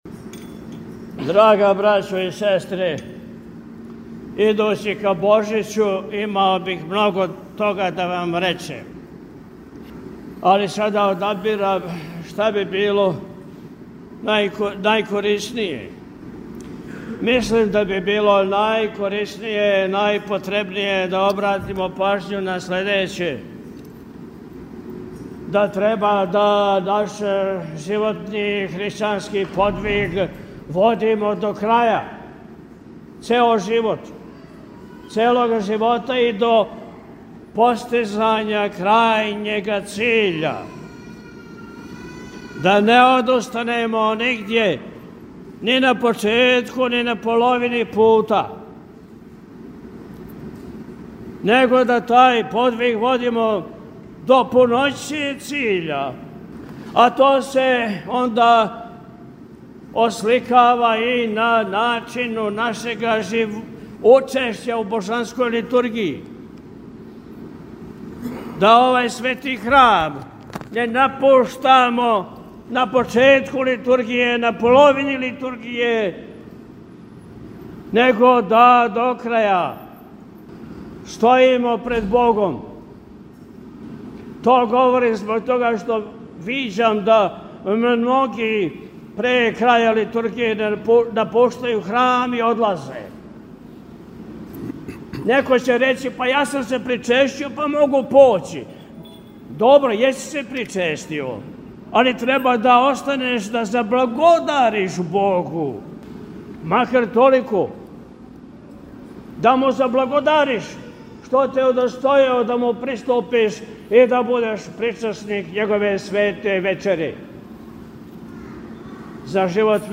На дан када Српска Православна Црква обележава празник Материце, 29. децембра 2024. године, Његово Високопреосвештенство Архиепископ и Митрополит милешевски г. Атанасије служио је Свету архијерејску Литургију у храму Васкрсења Христовог у Новом Прибоју.